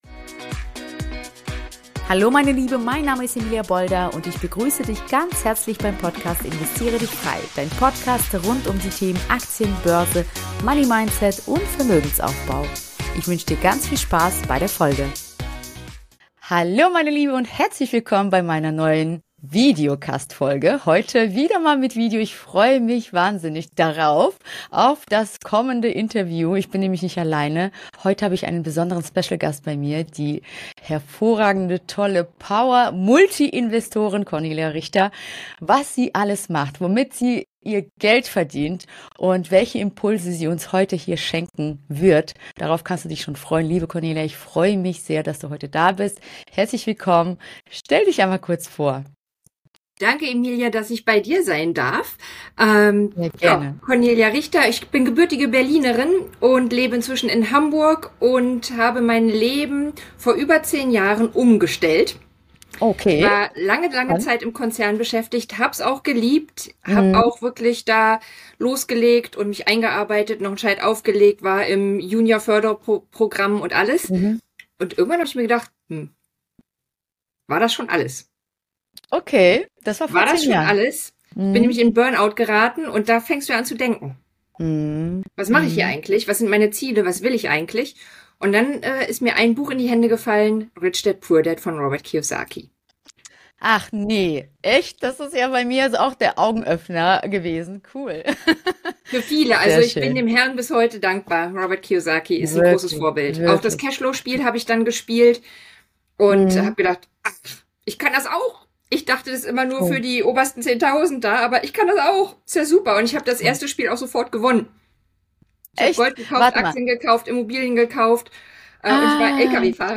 #59 Vom Network Marketing zur Multi-Investorin - Das Interview